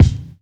INSKICK10 -L.wav